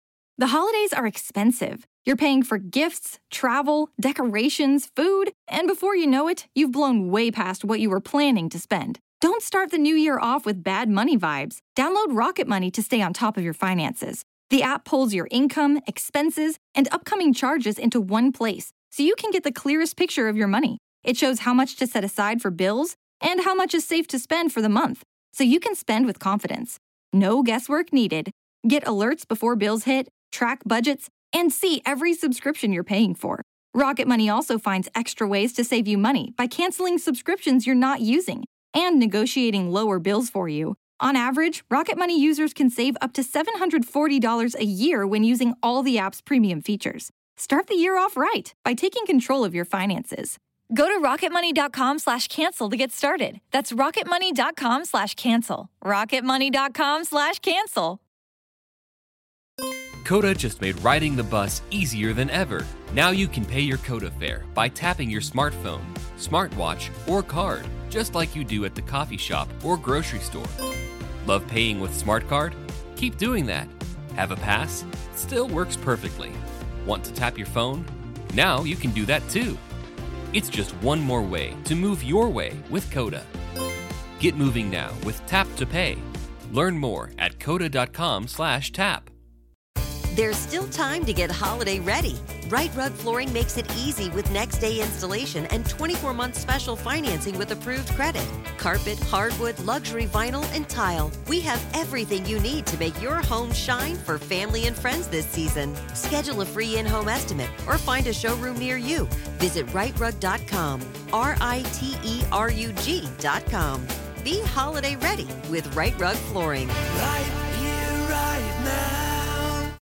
Comedians and dearest pals Tom Allen and Suzi Ruffell chat friendship, love, life, and culture... sometimes...